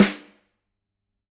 Index of /musicradar/Snares/Ludwig A
CYCdh_LudRimA-05.wav